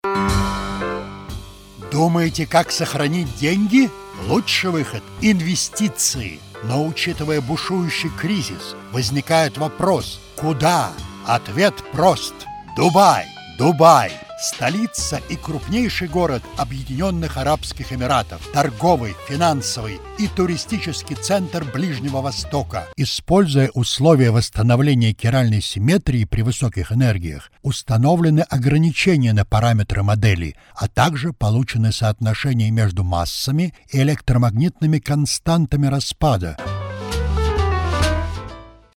Sprecher russisch, tiefe, volle Stimme
Sprechprobe: Werbung (Muttersprache):
Deep, reach voice